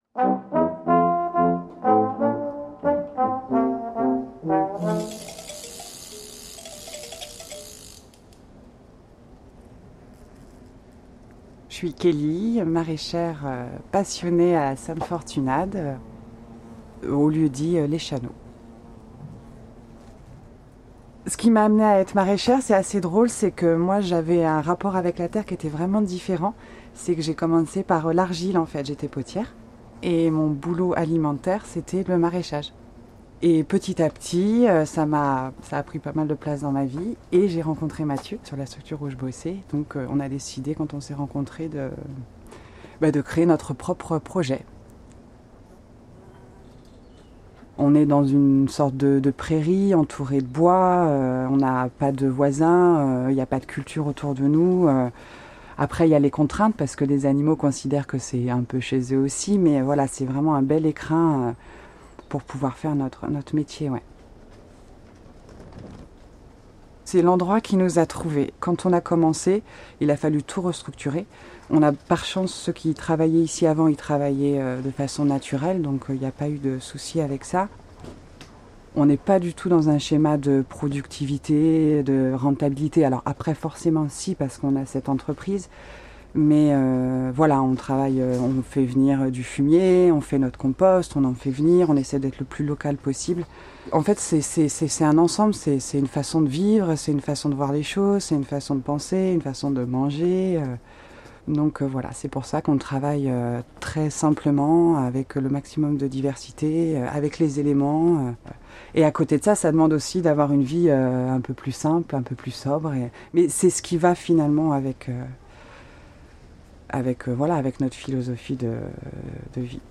portrait sonore